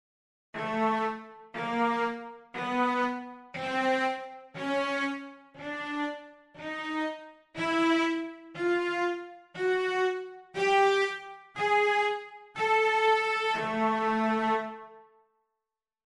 AA sharpBCC sharpDD sharpEFF sharpGG sharpA
twelve_tones.mp3